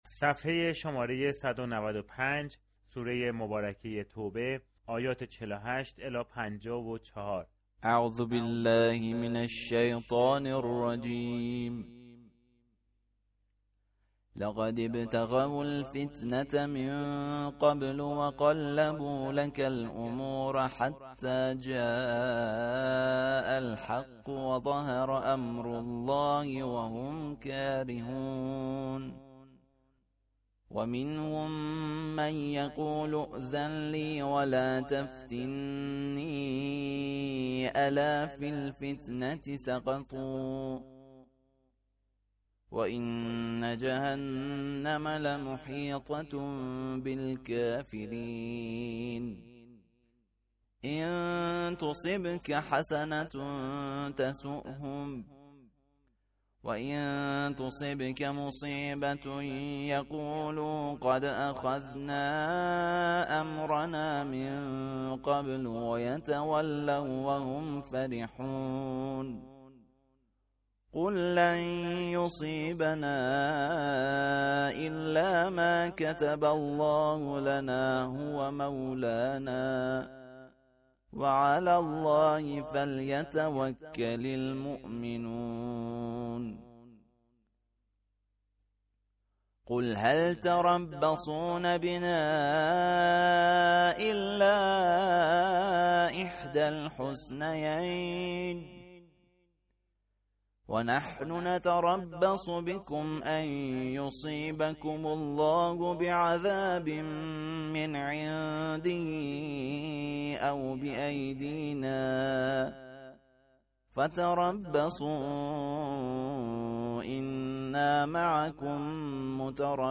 امروز سوره توبه ، آیات 48 تا 54 را با هم می خوانیم.